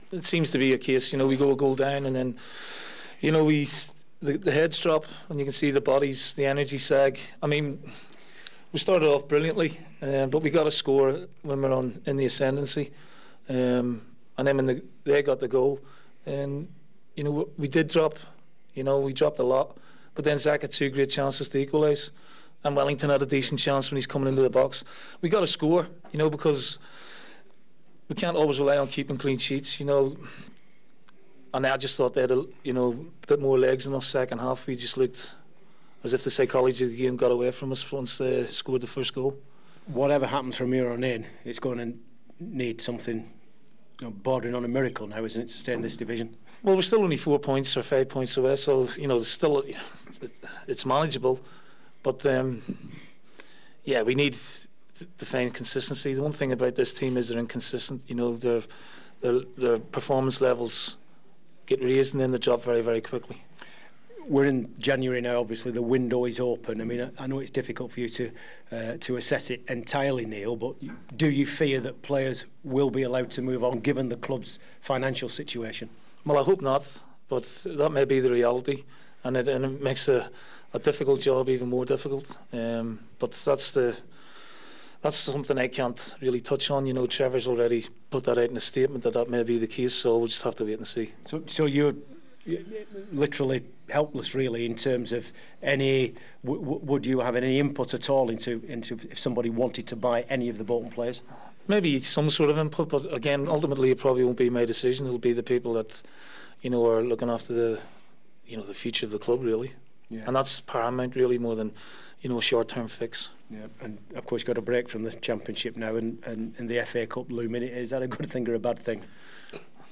Bolton manager Neil Lennon was downbeat after his side slumped to 2-0 defeat at home to Huddersfield, suggesting the team's problem was the inconsistency of their peformance levels.